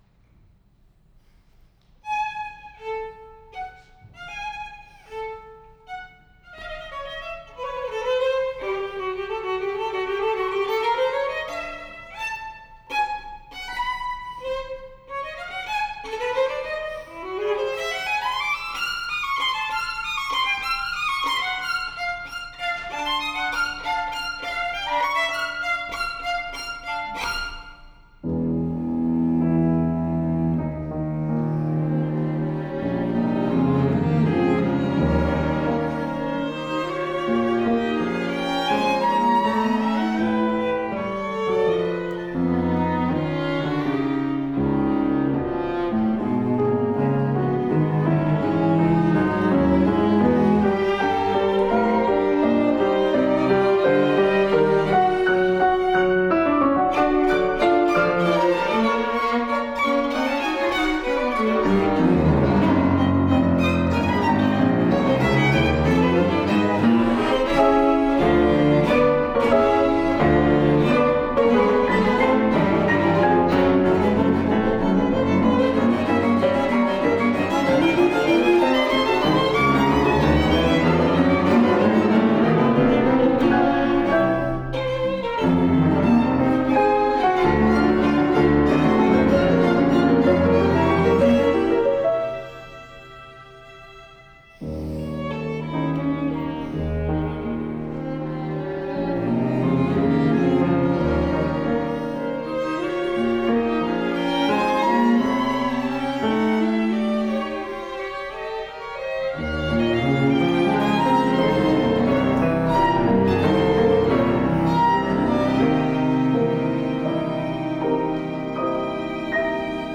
for Piano Quintet
violins
viola
piano.
However, as all of my music, this is strictly in the classical tradition, with no improvisation, and to be treated in the same way as other “serious” art music—even, and perhaps especially, when it’s intended to be for fun.